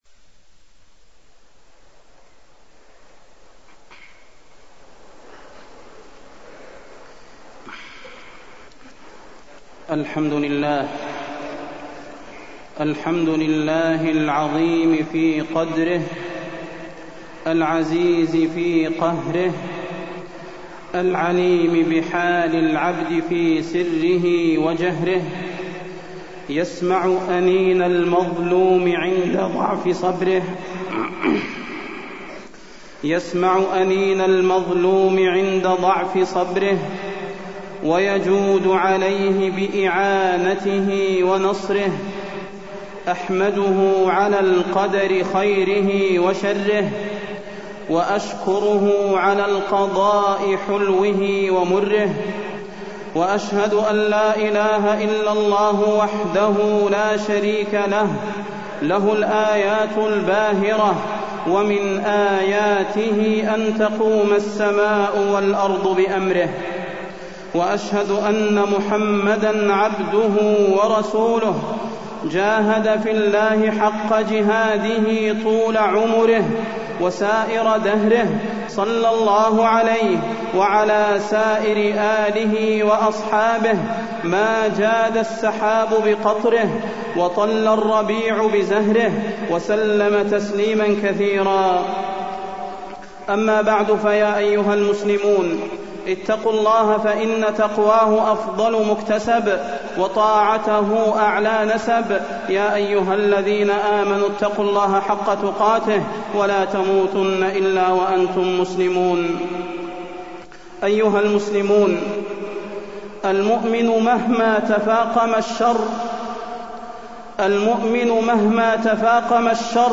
تاريخ النشر ١٦ شوال ١٤٢٣ هـ المكان: المسجد النبوي الشيخ: فضيلة الشيخ د. صلاح بن محمد البدير فضيلة الشيخ د. صلاح بن محمد البدير الظلم